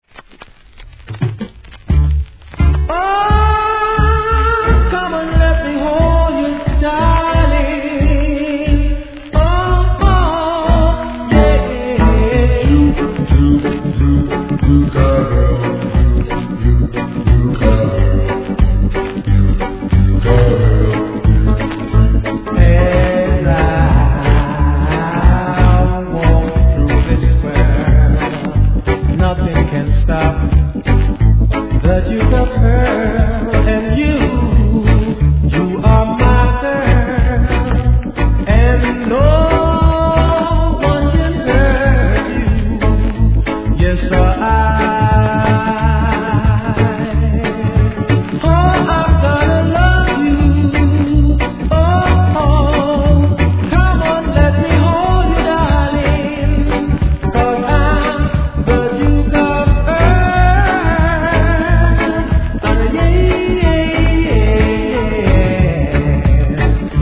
REGGAE
ROCKSTEADYカヴァー!